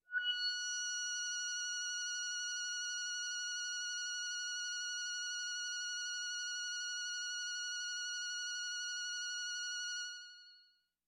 描述：通过Modular Sample从模拟合成器采样的单音。
Tag: F6 MIDI音符-90 罗兰木星-4 合成器 单票据 多重采样